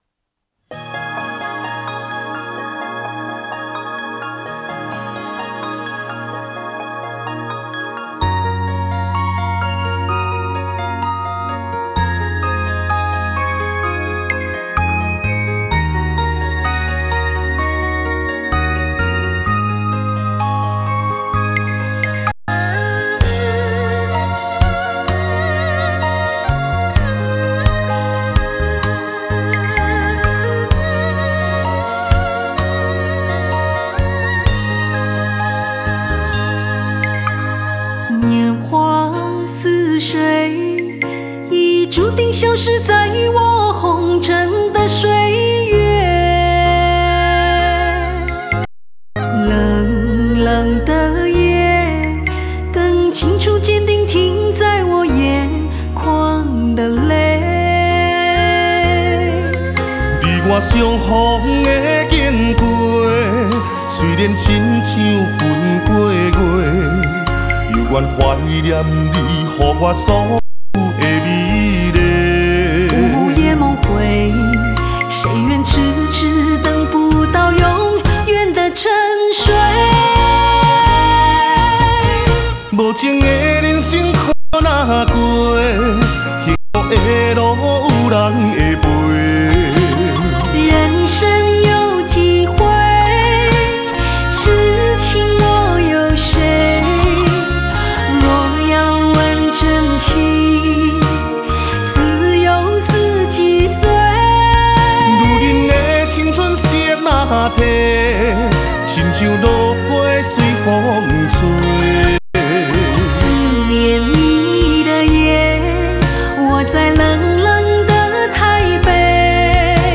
Ps：在线试听为压缩音质节选，体验无损音质请下载完整版 年华似水，已逐渐消失在我红尘的岁月。